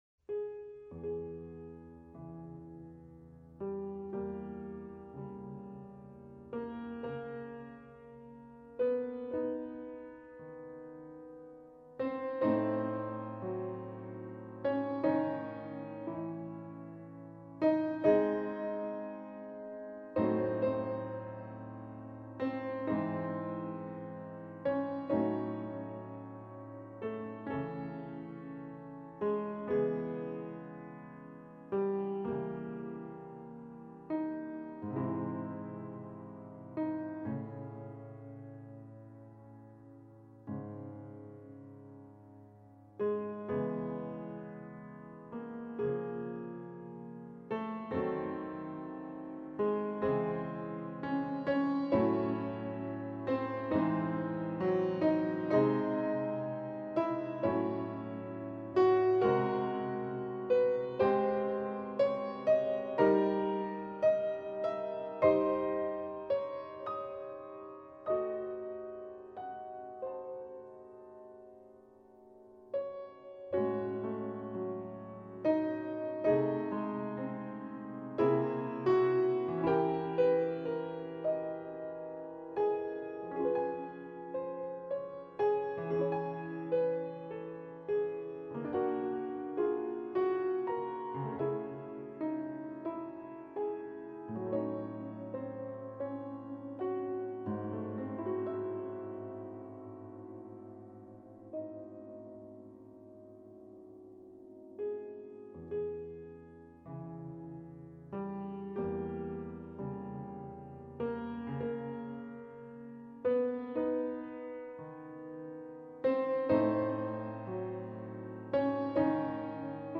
en do# mineur